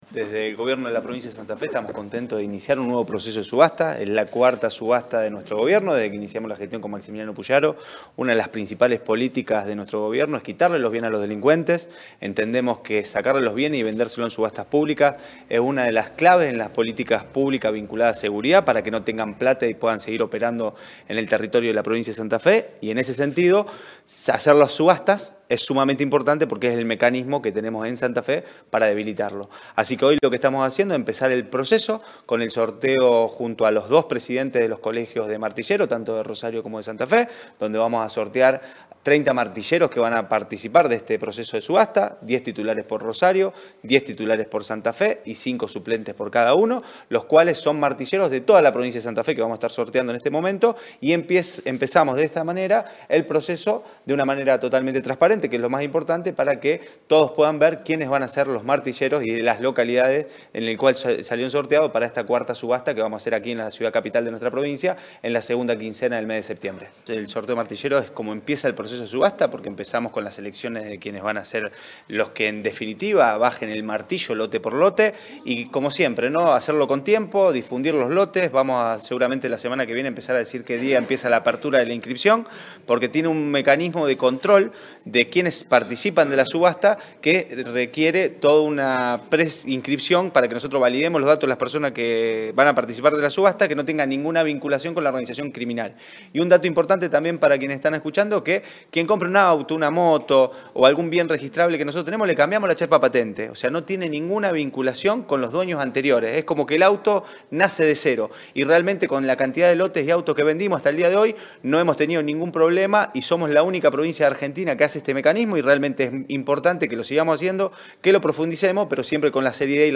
En conferencia de prensa, el secretario de Gestión de Registros, Matías Figueroa Escauriza, subrayó que “vamos a iniciar la cuarta subasta de bienes decomisados durante el gobierno de Maximiliano Pullaro, y hoy dimos el primer paso con el sorteo de 30 martilleros, con la presencia de los presidentes de los Colegios de Santa Fe y de Rosario”.